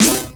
Snare Drum 66-12.wav